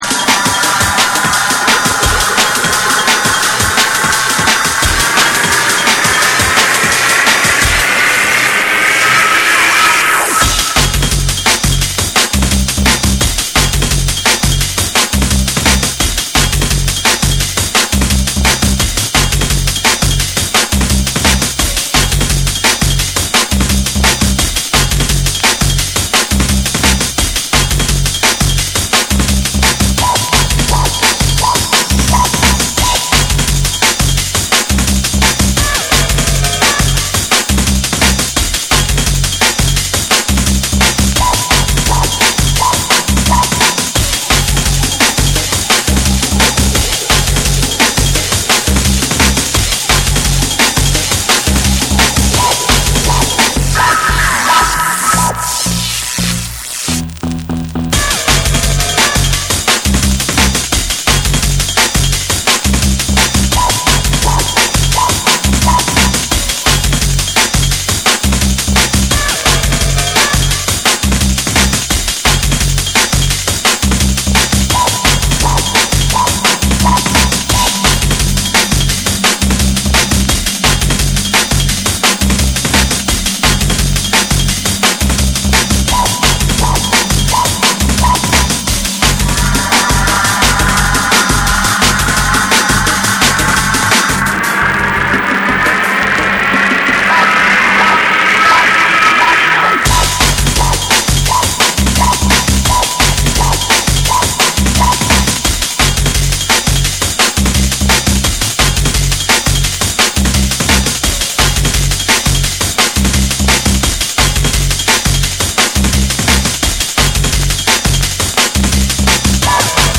攻撃的なブレイクビーツに、ハードステップなベースラインが炸裂する、フロア直撃の即戦力ドラムンベース・チューンを収録！